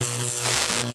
emp-electric-4.ogg